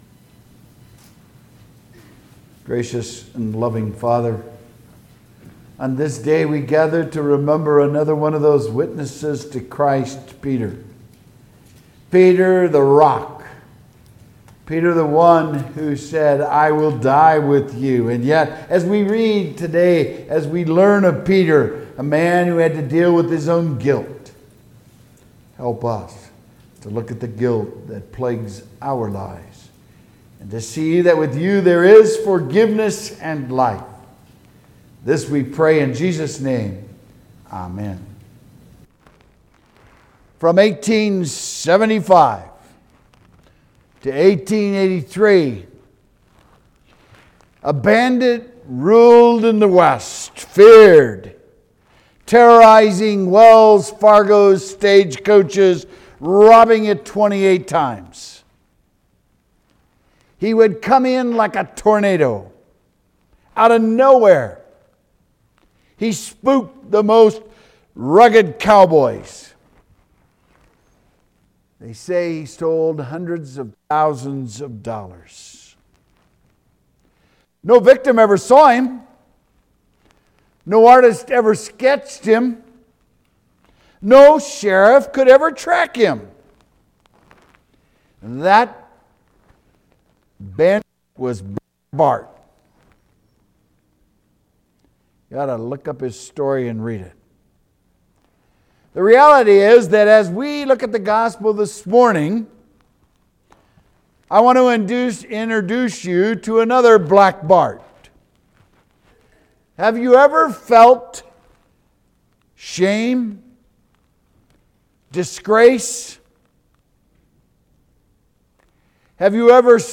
Witnesses to Christ: Peter March 23, 2022 Sermon